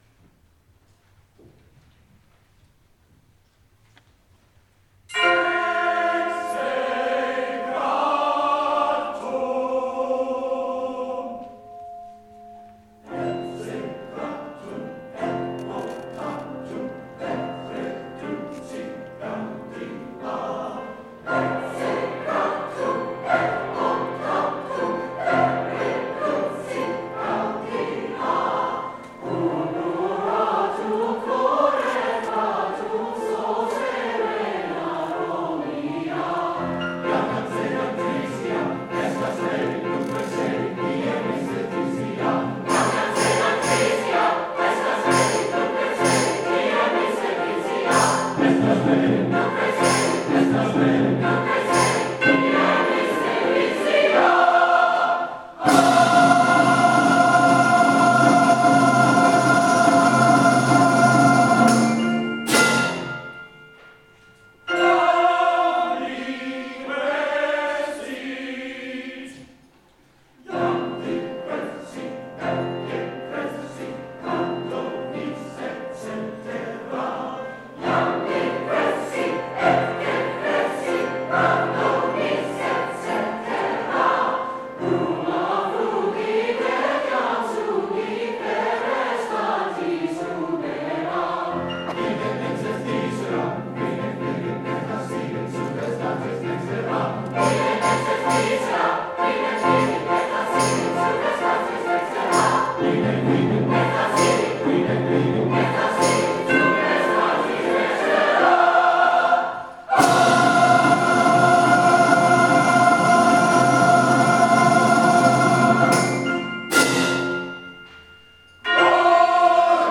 our May 4th concert
Ecce gratum     Coro